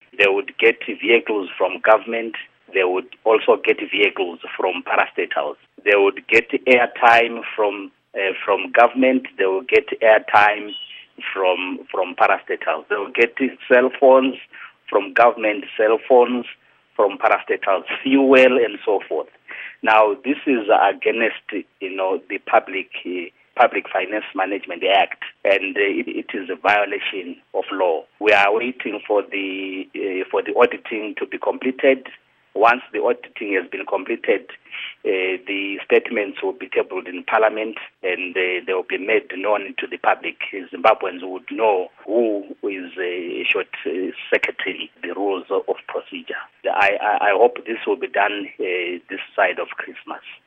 Interview With Gorden Moyo